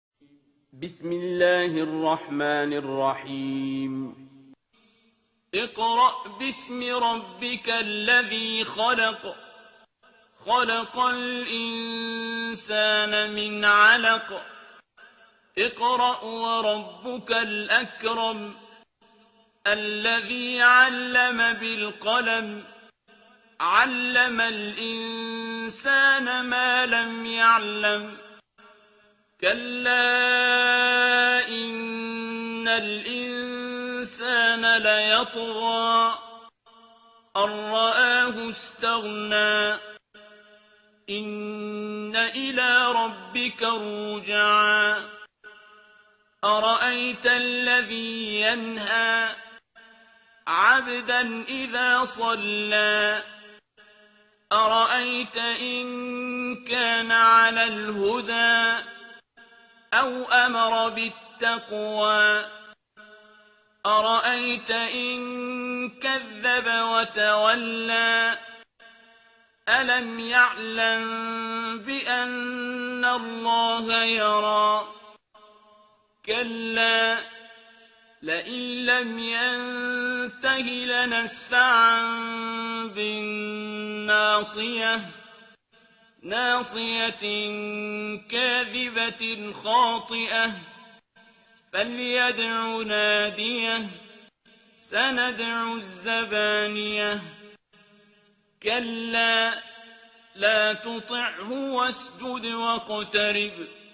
ترتیل سوره علق با صدای عبدالباسط عبدالصمد